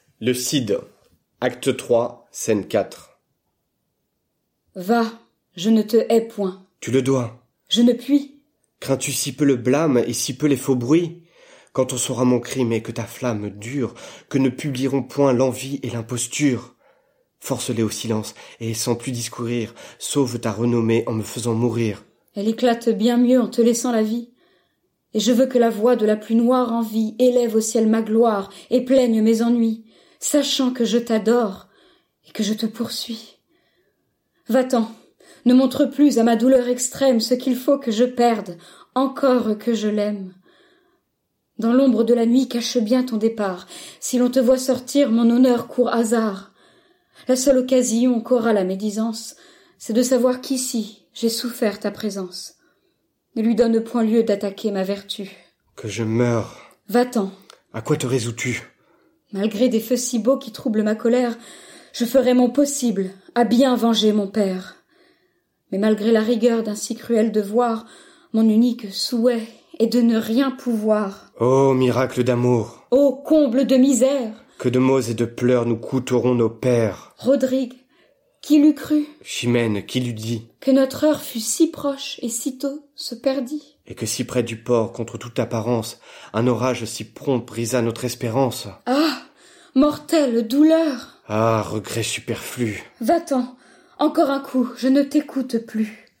lue par un comédien.